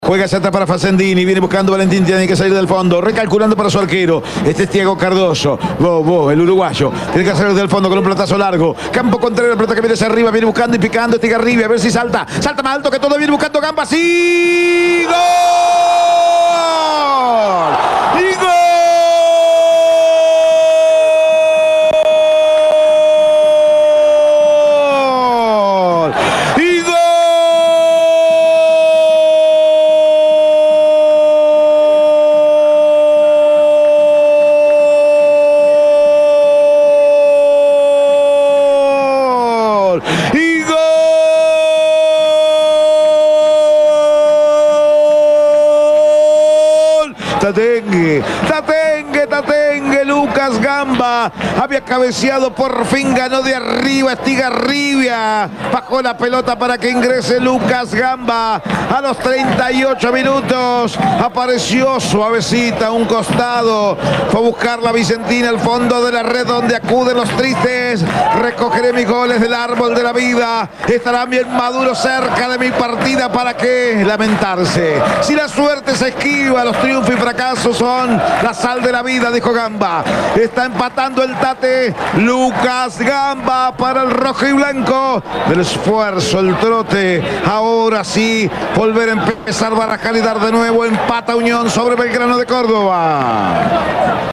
EL GOL DE UNIÓN, EN EL RELATO
GOL-1-UNION.mp3